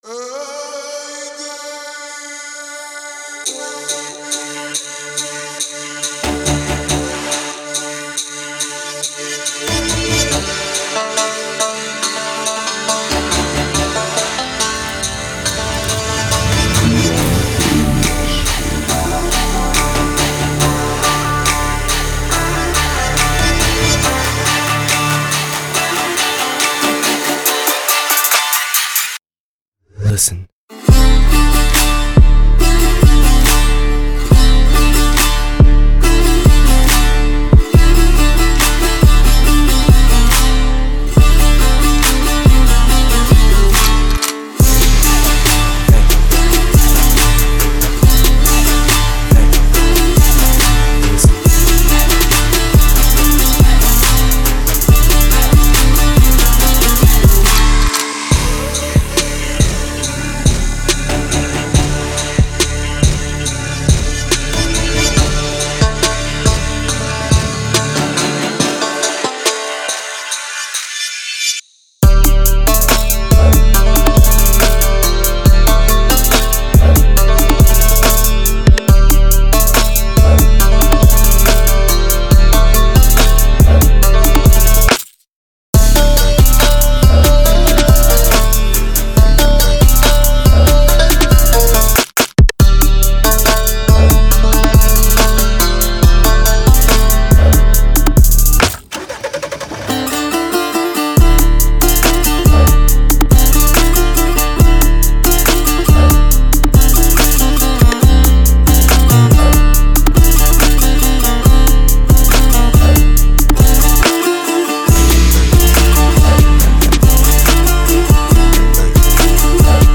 Download Iranian trap remix